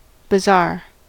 bazaar: Wikimedia Commons US English Pronunciations
En-us-bazaar.WAV